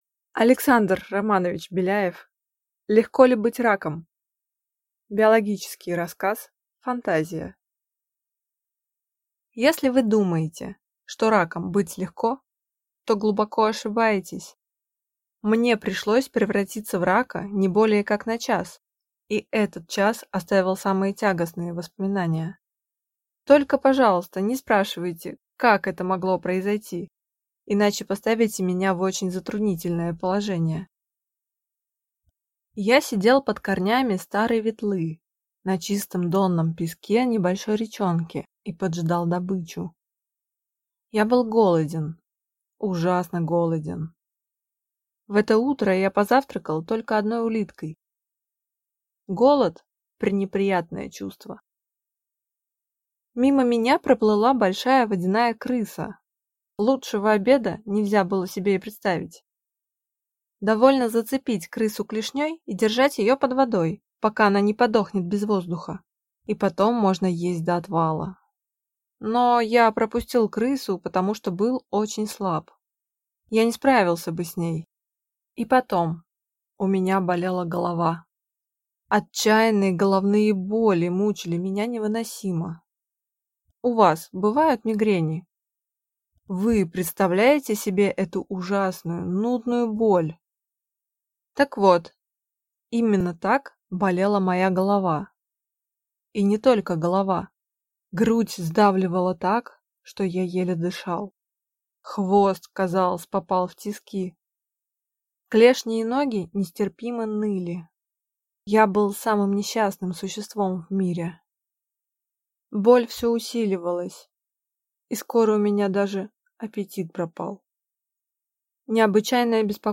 Аудиокнига Легко ли быть раком?